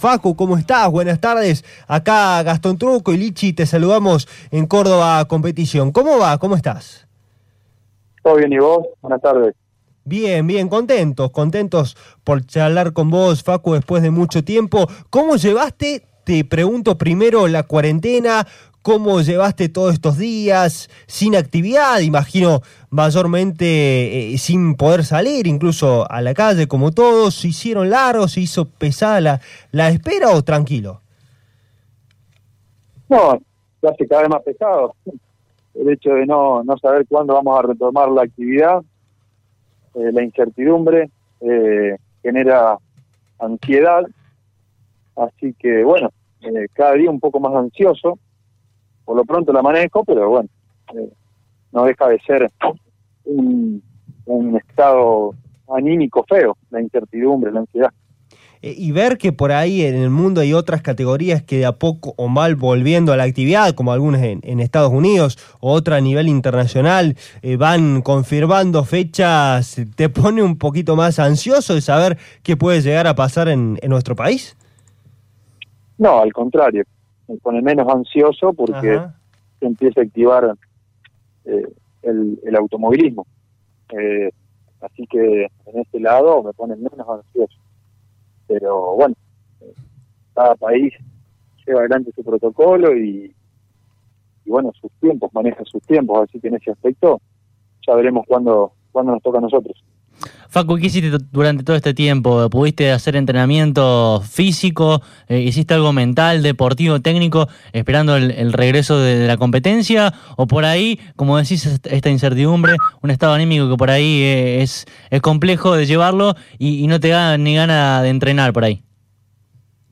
Este lunes, Facundo Ardusso pasó por los micrófonos de CÓRDOBA COMPETICIÓN.